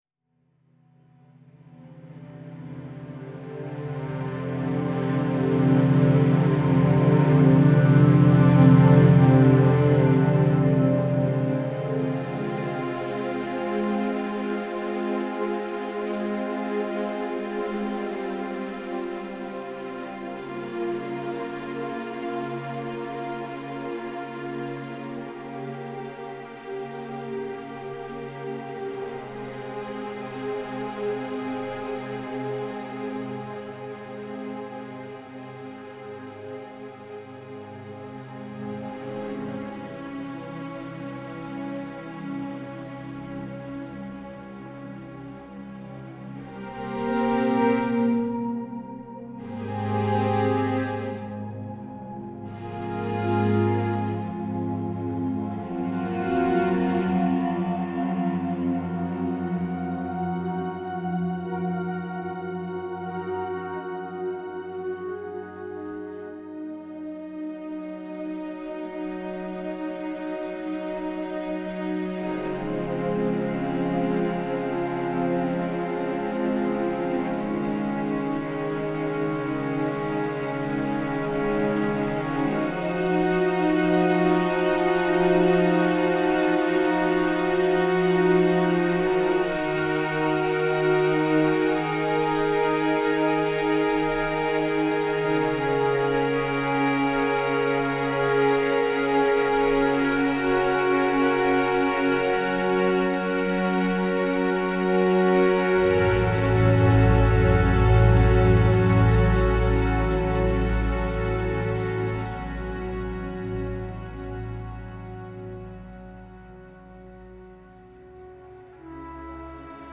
Drama - Tension